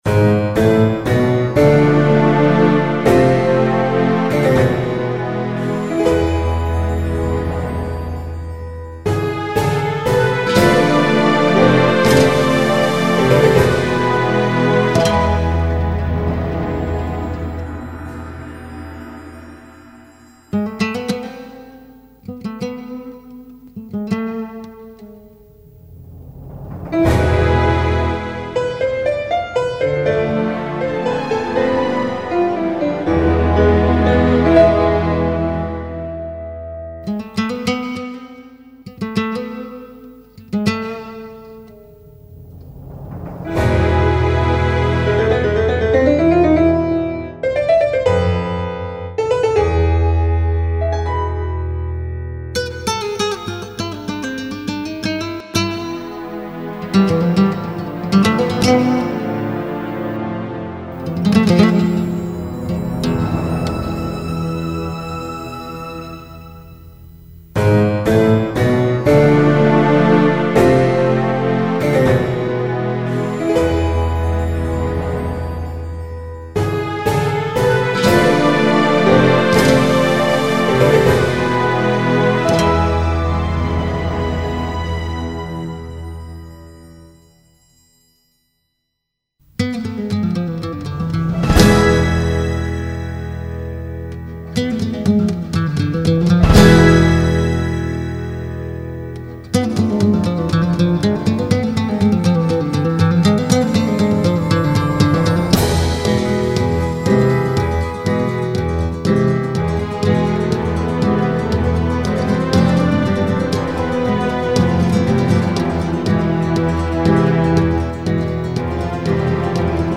Zambra